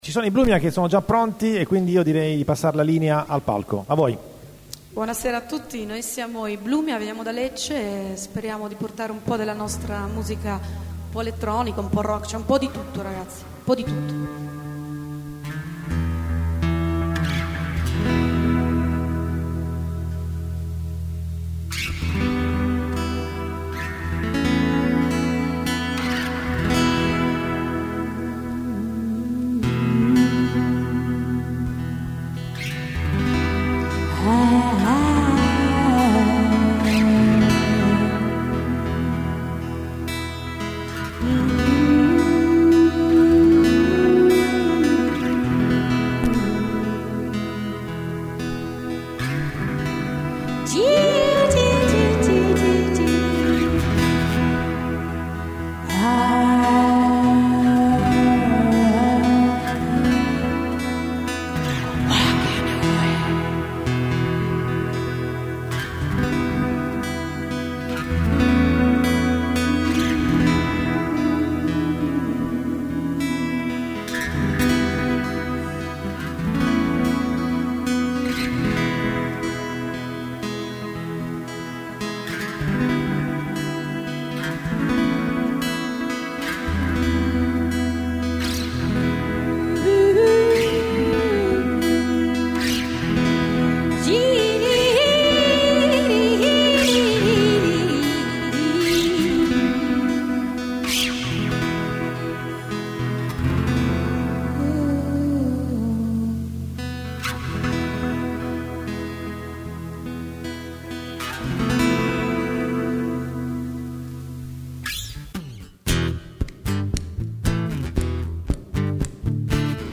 Voce, Sax, Armonica
Batteria, Cori, Programming, Piano, Synth, Chitarra